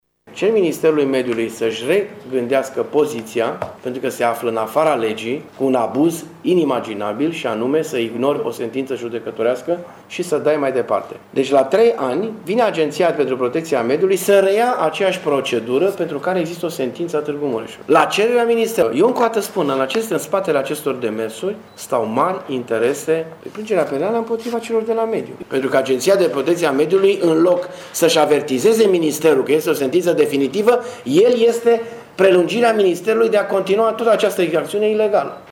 Dorin Florea a spus că, dacă acest lucru nu se va întâmpla, va face plângere penală împotriva ministerului şi Agenţiei pentru Protecţia Mediului Mureş: